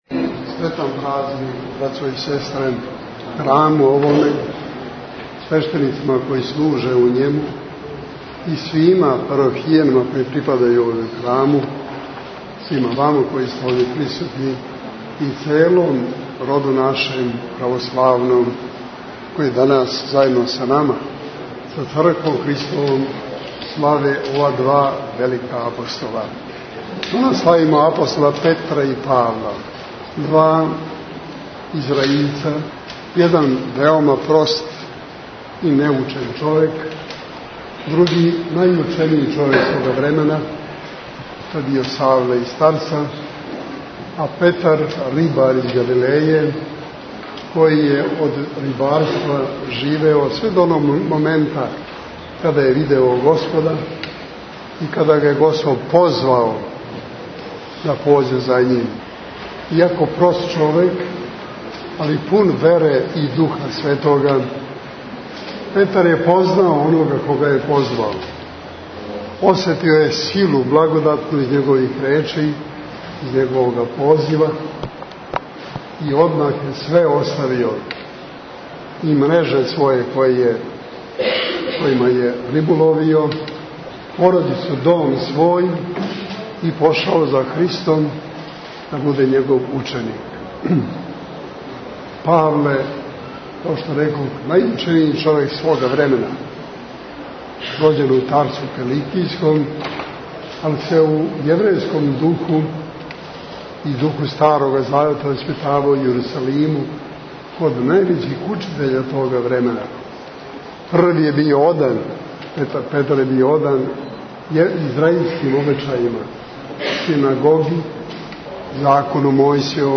Најстарија београдска црква, Светих Апостола Петра и Павла на Топчидеру, задужбина Кнеза Милоша, подигнута 1834. године прославила је у уторак 12. јула крсну славу. Свету Архијерејску Литургију, уз саслужење више свештеника и ђакона и појање хора овога храма, служио је Његова Светост Патријарх Српски Господин Иринеј.
Патријарх Иринеј је бесједио о апостолима Петру и Павлу који су својим ријечима, чудима, животима и смрћу свједочили Христа.